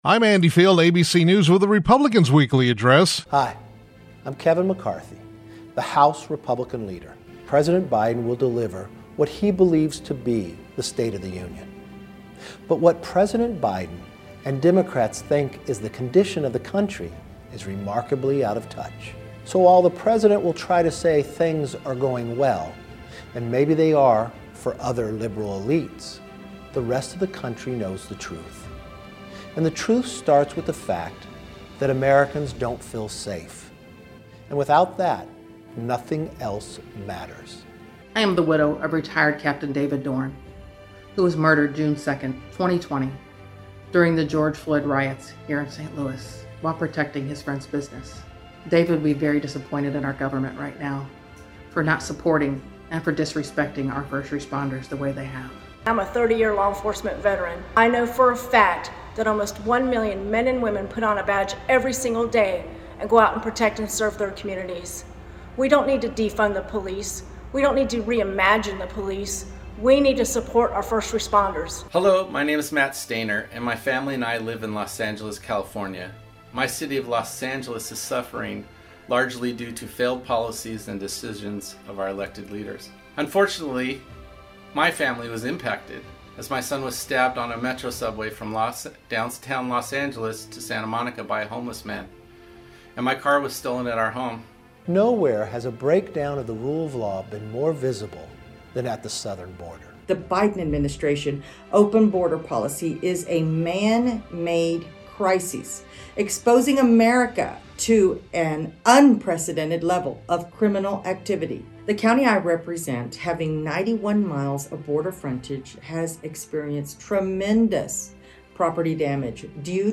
House Republican Leader Kevin McCarthy (CA-23) spoke about what he believes to be the real State of the Union ahead of tonight’s State of the Union Address by President Joe Biden.
McCarthy was Tuesday’s KVML “Newsmaker of the Day”.